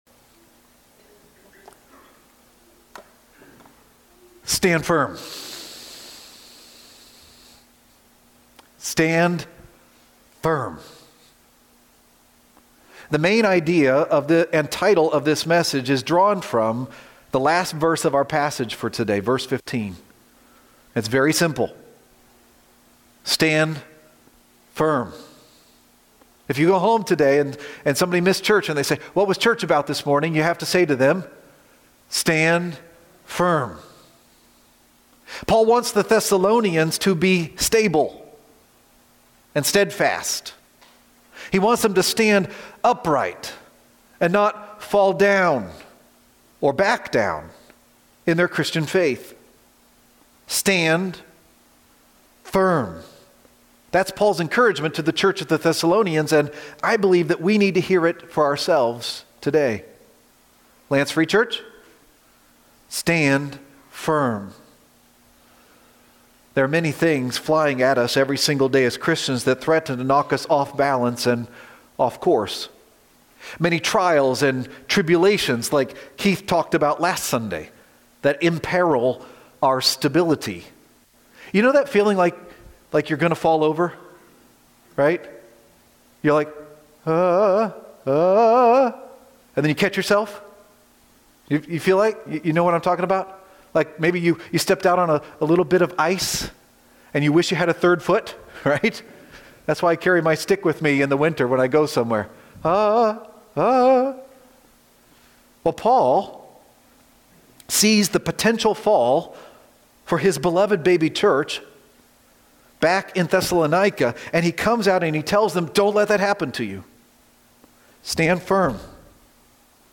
Stand Firm :: September 14, 2025 - Lanse Free Church :: Lanse, PA